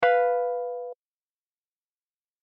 Now listen to the tonic and this flattened fifth degree together:
Tritone
Unsettling, don’t you think?
B-and-F-together.mp3